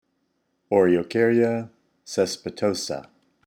Pronunciation/Pronunciación:
O-re-o-cár-ya  caes-pi-tò-sa